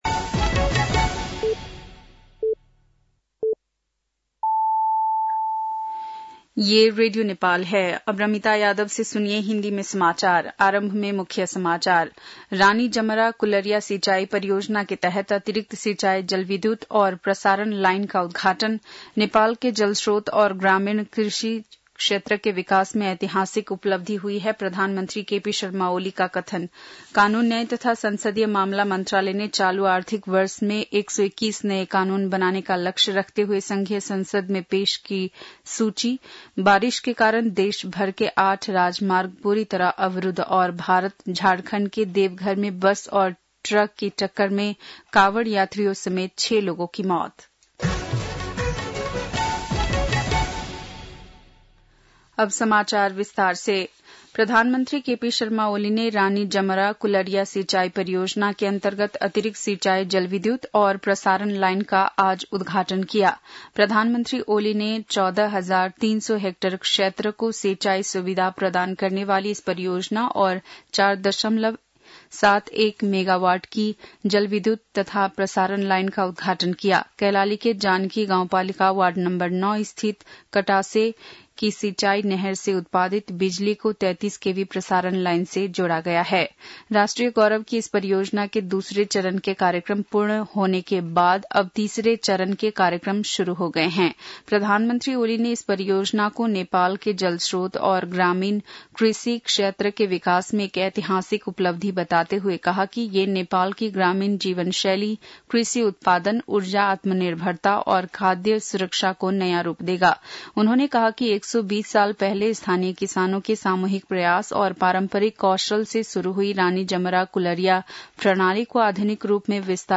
बेलुकी १० बजेको हिन्दी समाचार : १३ साउन , २०८२
10-pm-hindi-news-4-13.mp3